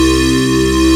Index of /90_sSampleCDs/Sound & Vision - Gigapack I CD 2 (Roland)/SYN_ANALOG 2/SYN_Analog 8